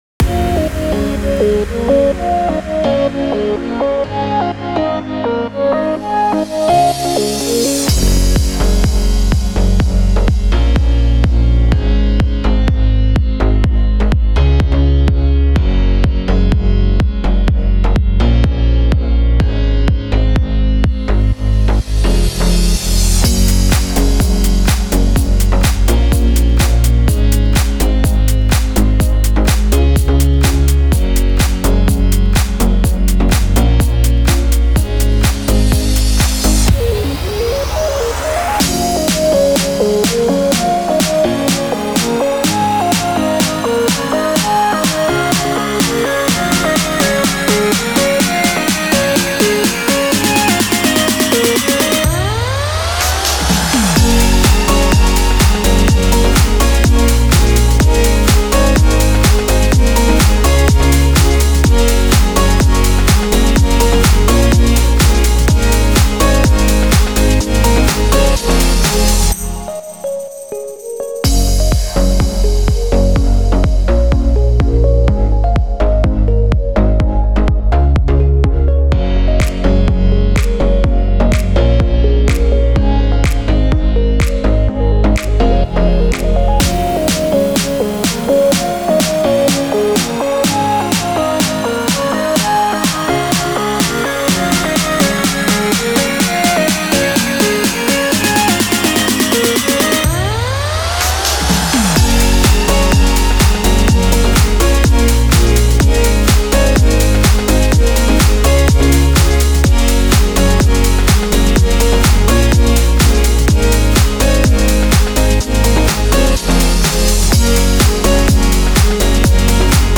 Hovedvignett
Kun Lydspor